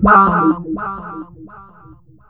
VOX FX 6  -R.wav